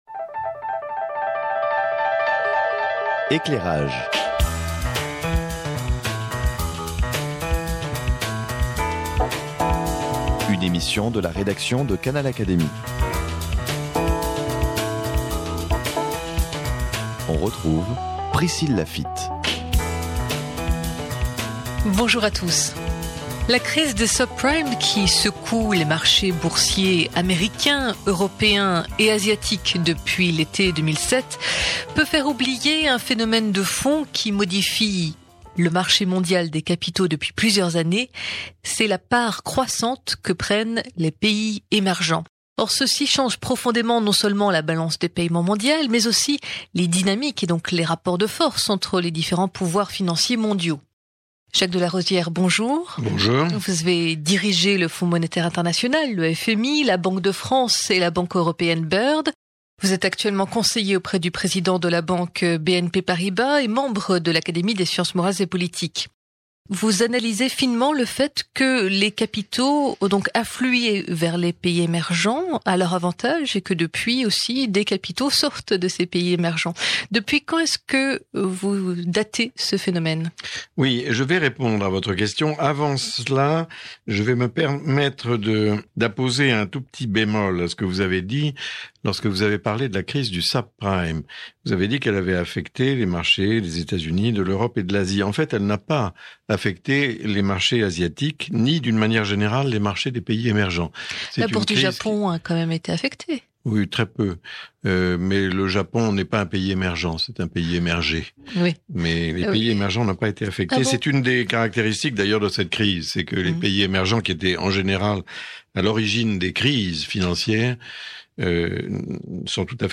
Analyse de Jacques de Larosière, ancien directeur général du FMI.